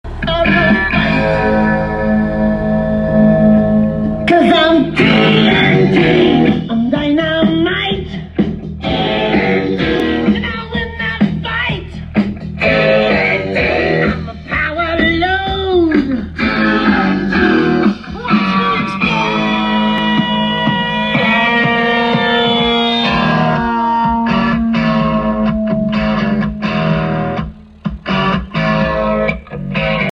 Nya middarna i fram från gs audio 10” neo och ett tt PRO horn spelar på duktigt